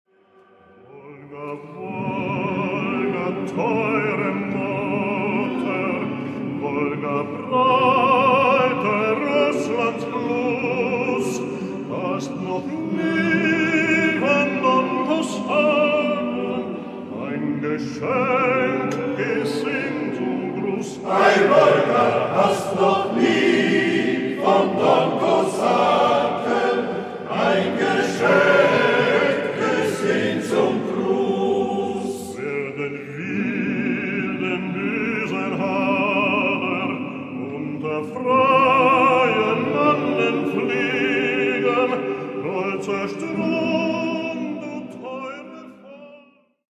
Solist